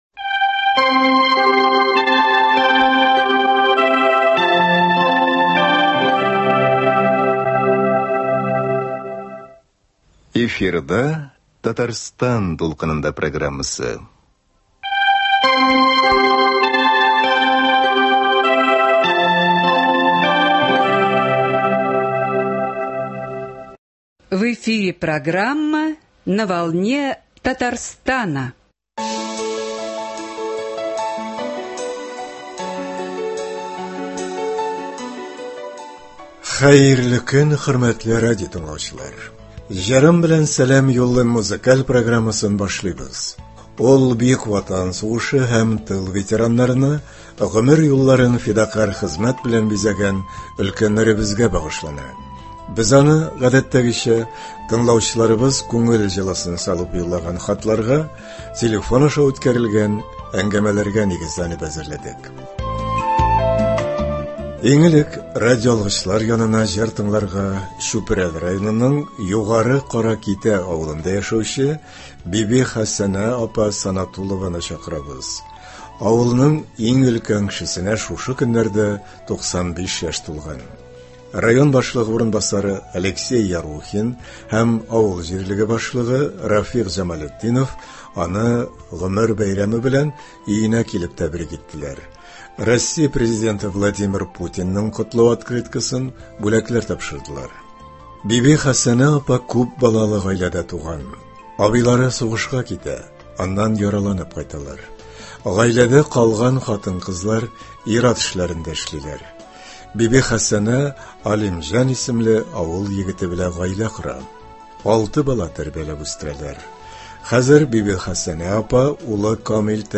Ветераннар өчен музыкаль программа